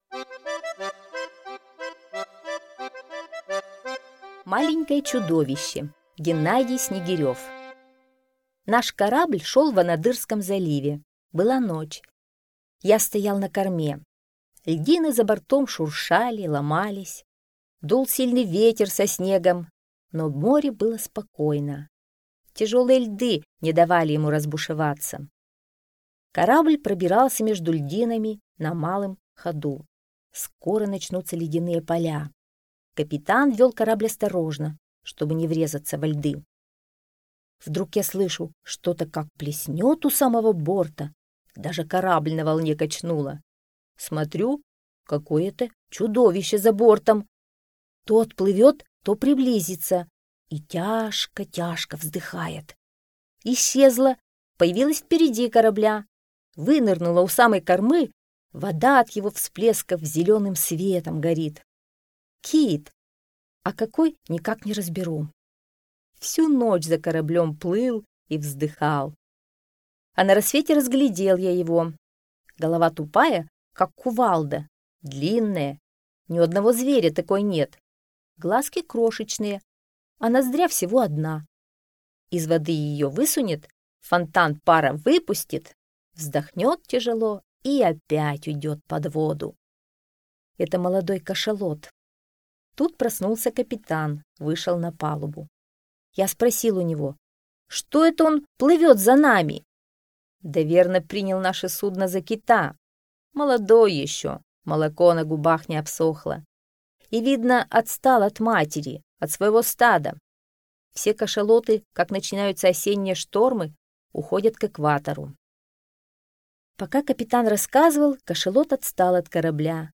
Маленькое чудовище - аудио рассказ Снегирева Г.Я. Однажды автор на корабле шел в Анадырском заливе и за ними стал плыть молодой кашалот.